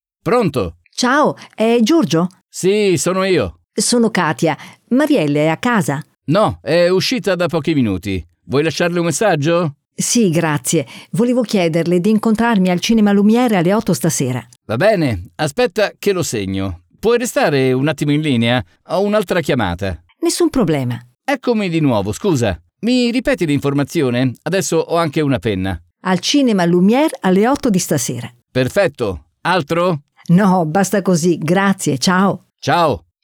hasznos párbeszédek audióval